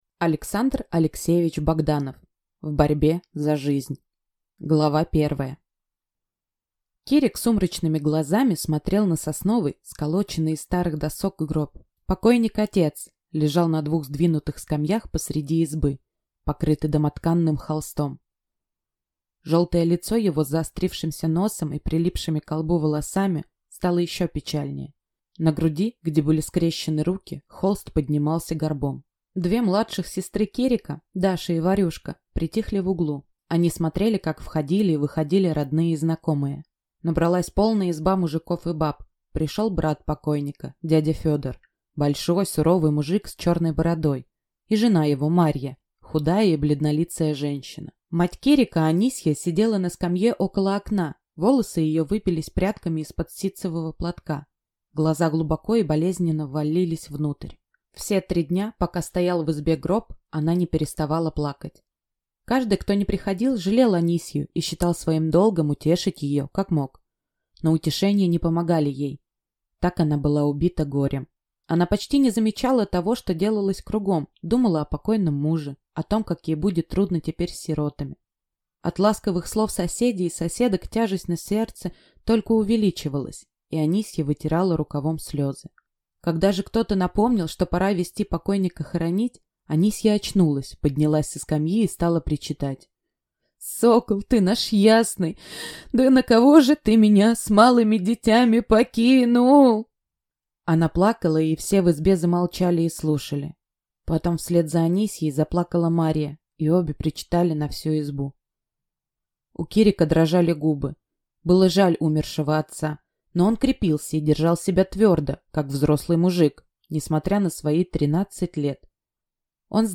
Аудиокнига В борьбе за жизнь | Библиотека аудиокниг